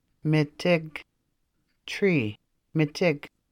Anishinaabemowin Odawa: Mitig    [Mi tig]